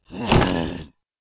behemoth_attack1g.wav